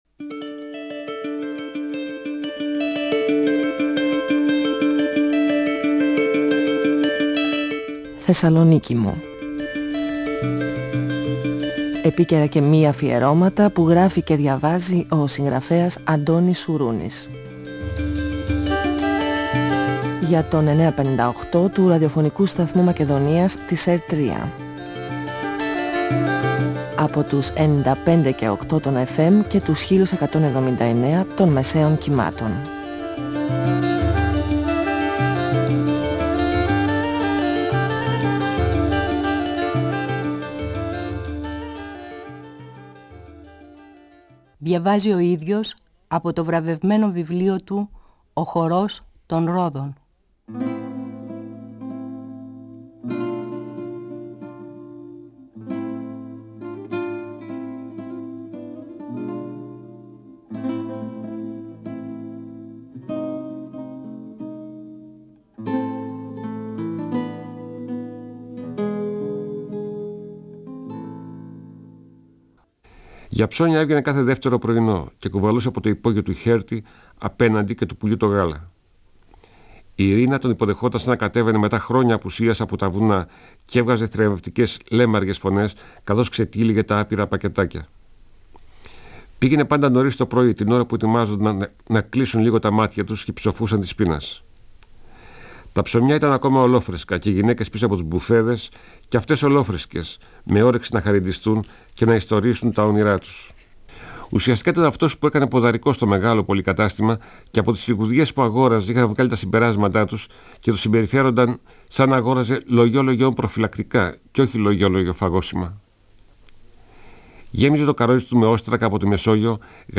Ο συγγραφέας Αντώνης Σουρούνης (1942-2016) διαβάζει από το βιβλίο του «Ο χορός των ρόδων», εκδ. Καστανιώτη, 1994. Ο Νούσης αγοράζει λογής λογής λιχουδιές για την Ιρίνα και δοκιμάζουν κρασιά.